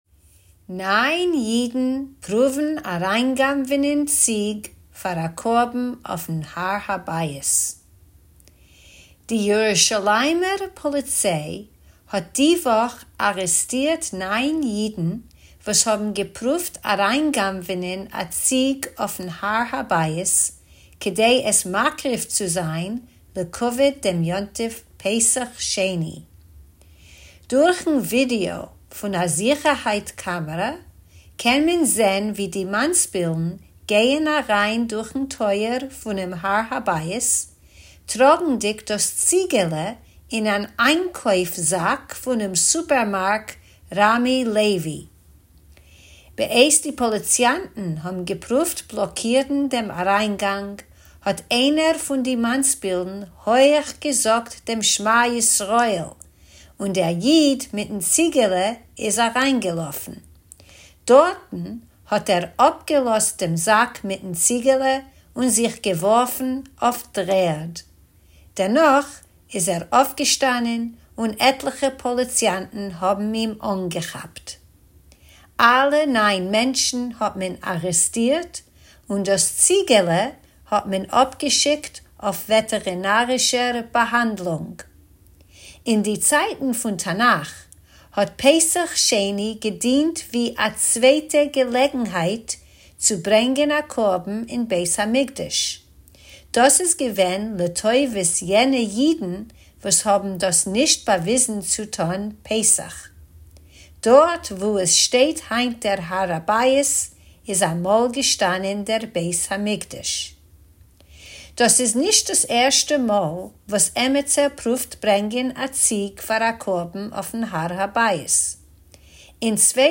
Tidbits is a Forverts feature of easy news briefs in Yiddish that you can listen to or read, or both!